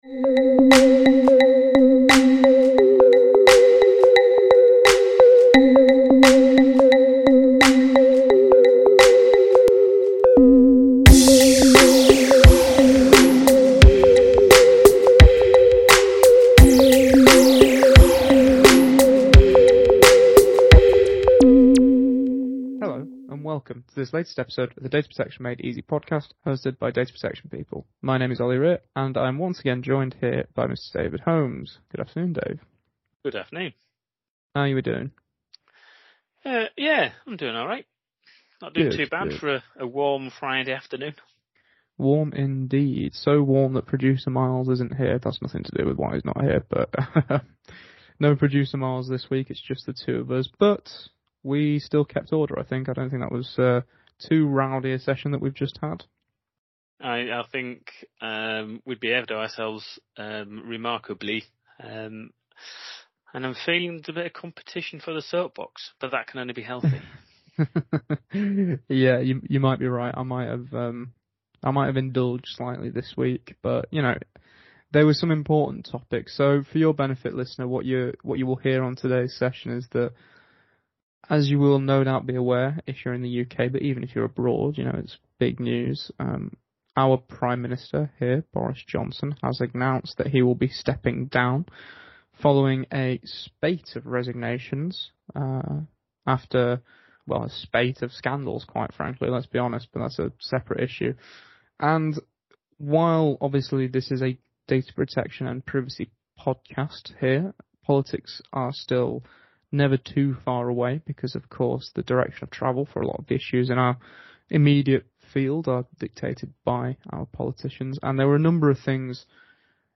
In this episode of the Data Protection Made Easy Podcast, our hosts delve into the news of the week to share their views and opinions with our growing audience of data protection practitioners.